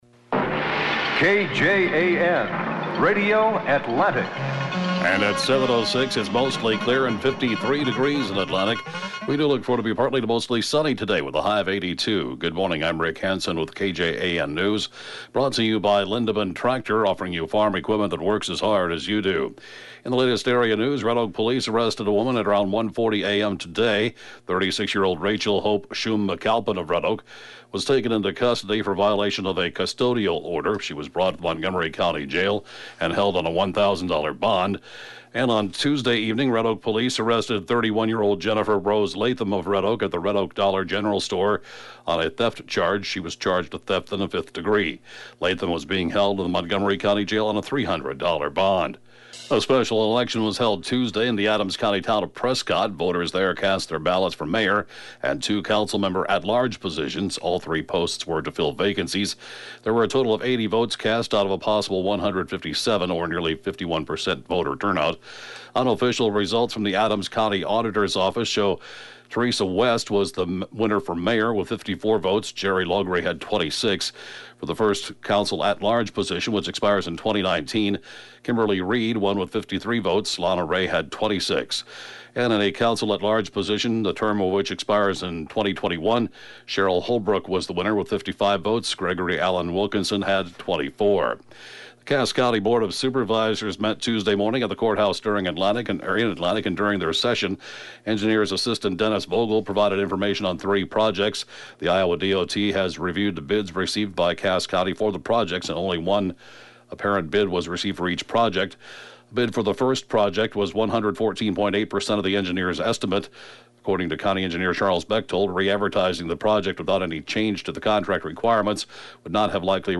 (Podcast) KJAN Morning News & funeral report, 4/7/2018